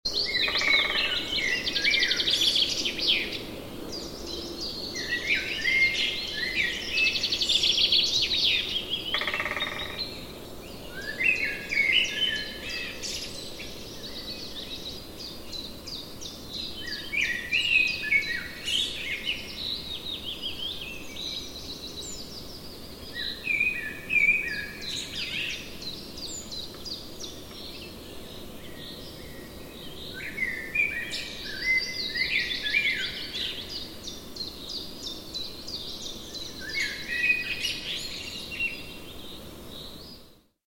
Paukščių balsą skambučiui galite rasti
Pauksciu-balsas-skambuciui-4.mp3